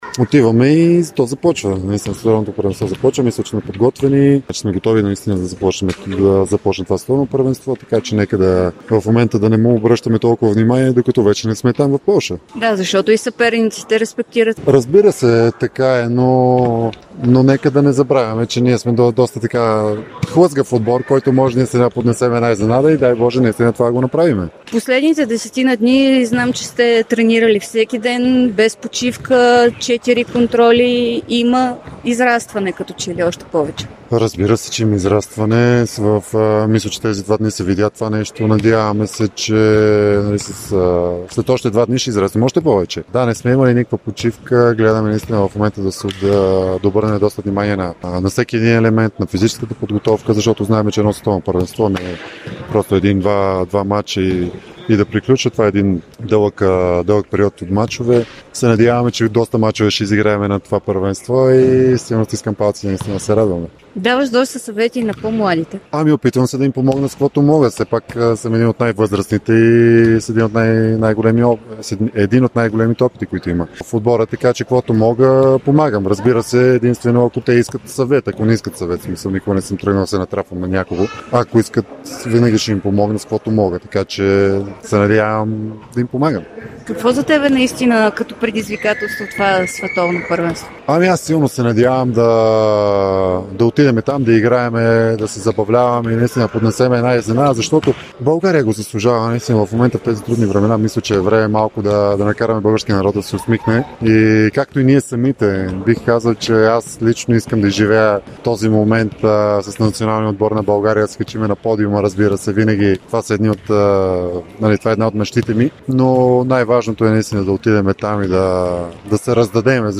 Един от най-опитните играчи в националния отбор на България по волейбол Цветан Соколов говори пред Дарик и dsport след втората контрола на "трикольорите" срещу Канада, която бе последен приятелски мач за тима преди началото на Световното първенство в Полша.